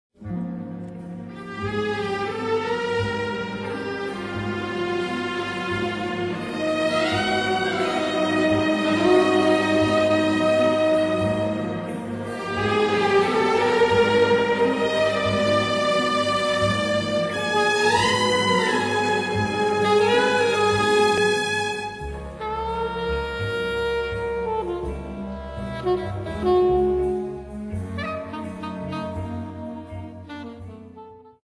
Konzertstück für Big Band und Streicher